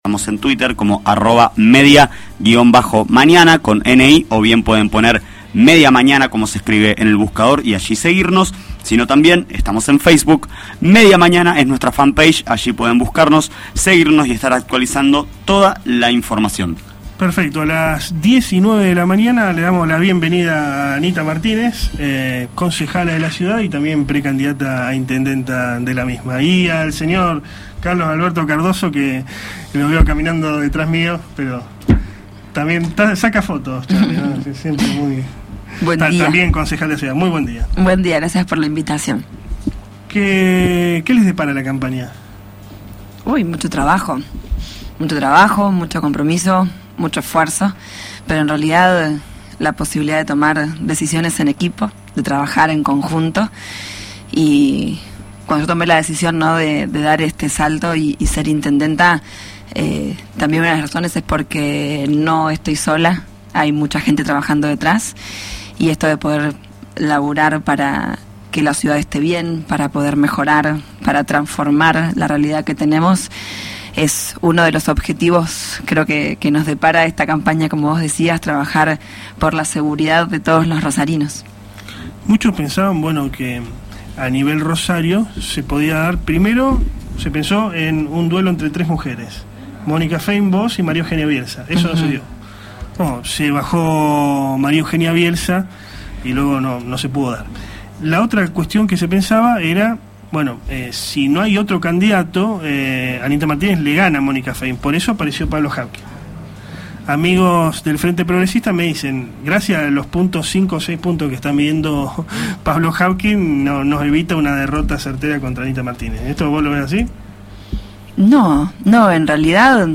ANITA MARTÍNEZ AUDIO ENTREVISTA
Media Mañana, programa que se emite de lunes a viernes de 9 a 11 hs, por FM “El Cairo”, 105.7 de Rosario, entrevistó a Ana Laura «Anita» Martínez, concejala en funciones y también Precandidata a Intendenta de la ciudad por Propuesta Republciana (PRO).